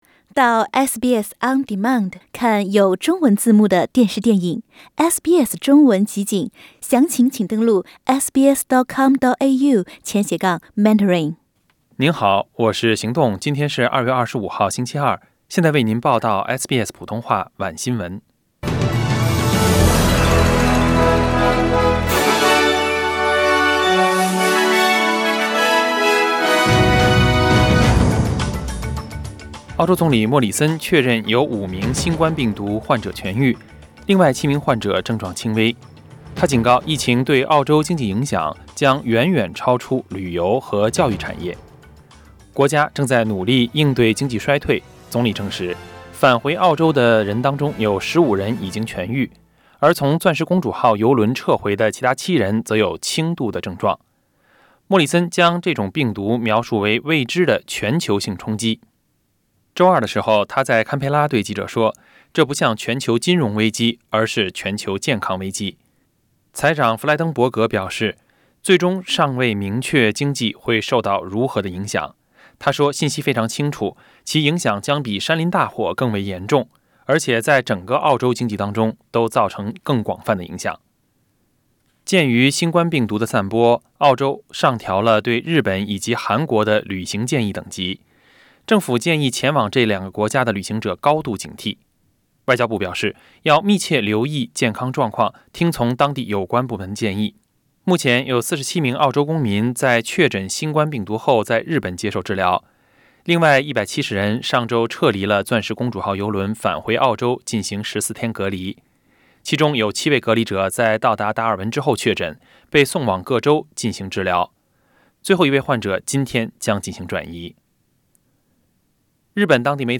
SBS晚新闻（2月25日）